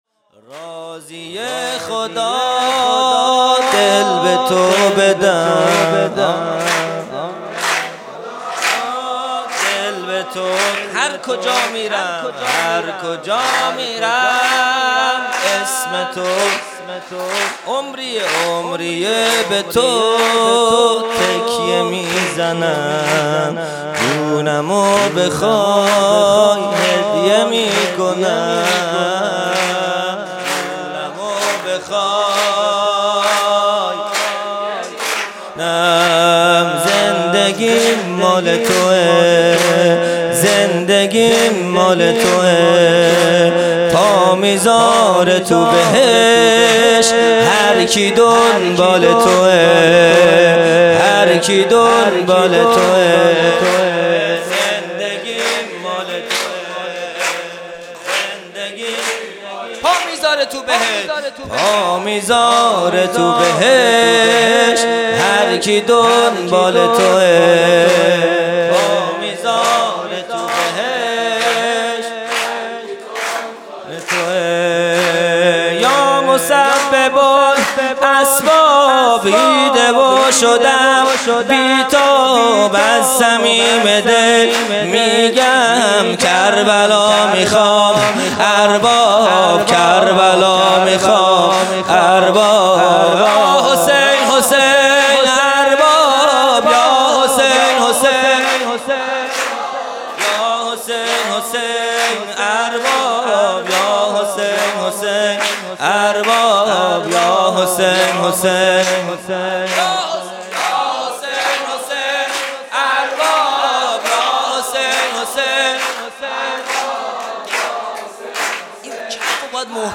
خیمه گاه - هیئت بچه های فاطمه (س) - سرود | راضیه خدا، دل به تو بدم | پنج شنبه ۱۹ اسفند ۱۴۰۰